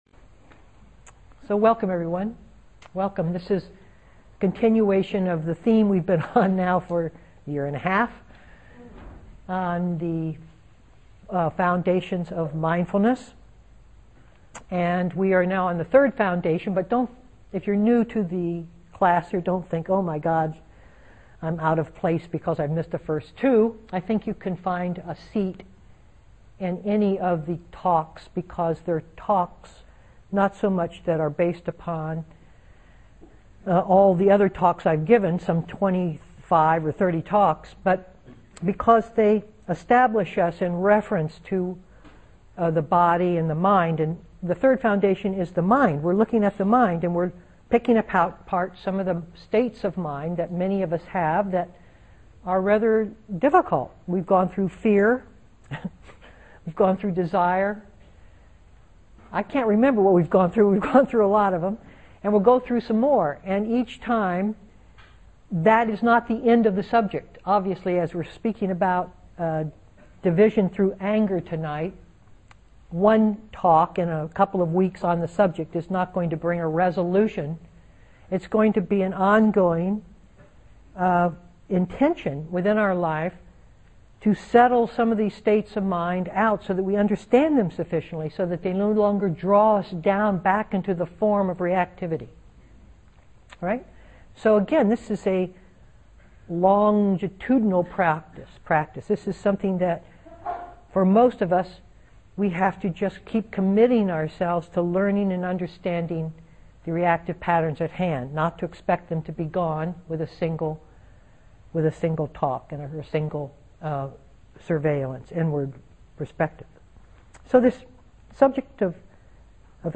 2011-04-12 Venue: Seattle Insight Meditation Center